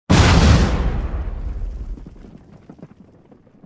YouHit4.wav